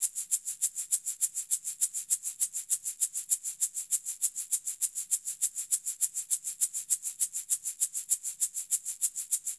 Shaker 01.wav